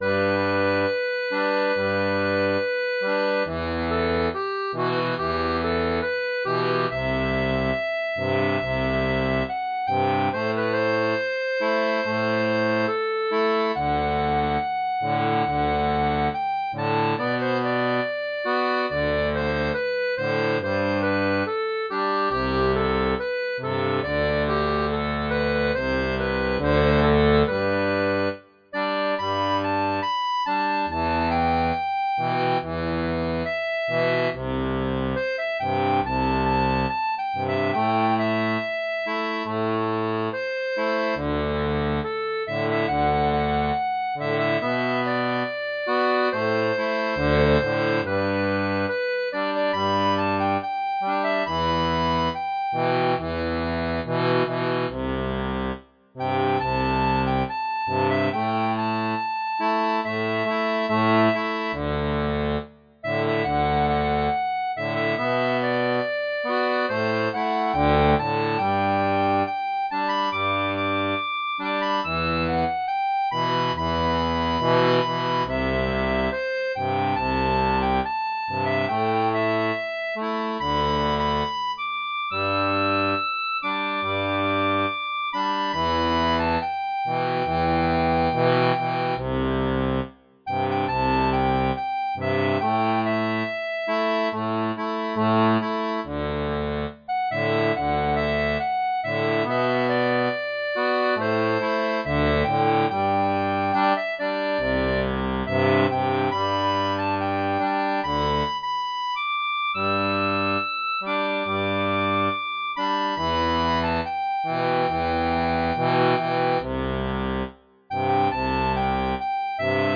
• Une tablature transposée en Sol « Aigue »
Pop-Rock